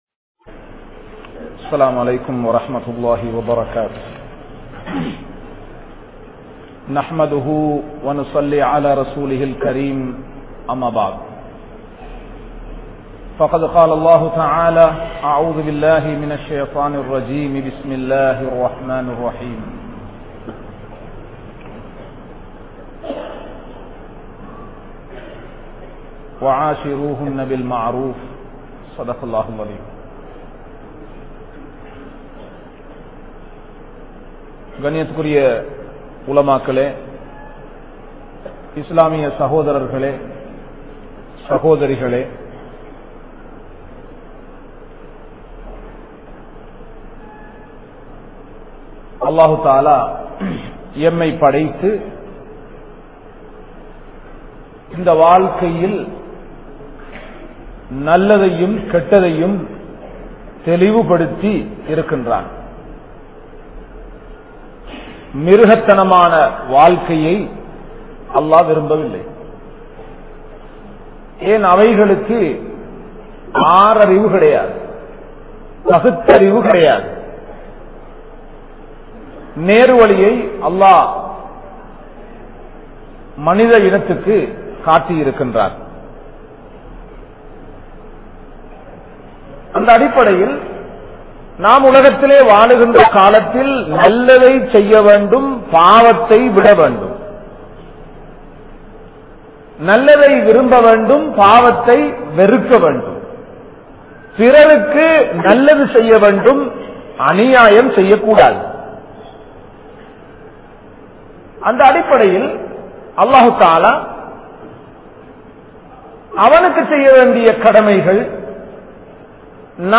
Kanavan Manaivien Uravuhal | Audio Bayans | All Ceylon Muslim Youth Community | Addalaichenai